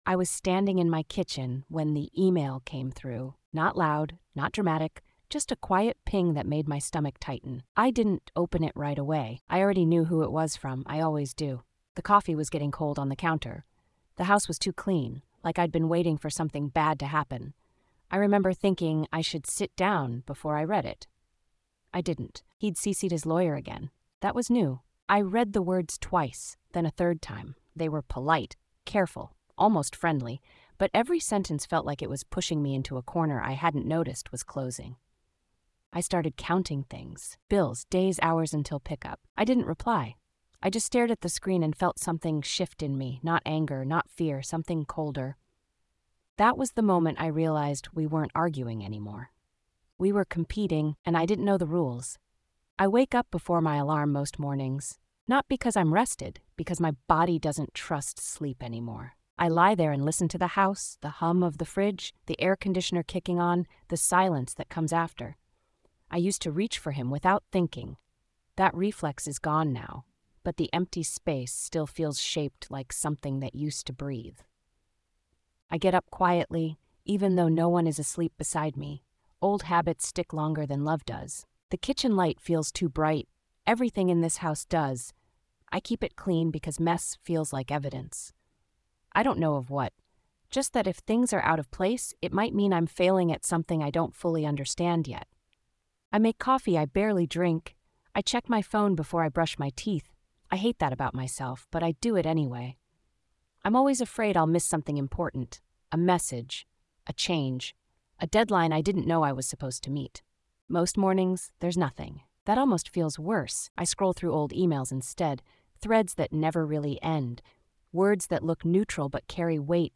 A woman recounts the quiet unraveling of her divorce as it shifts from separation into something colder and more strategic. What begins as routine communication turns into a calculated struggle for control, played out through emails, schedules, forms, and silence. Told entirely in her own voice, this episode traces how power moves through systems, language, and patience—and how surviving it requires learning a new way to exist.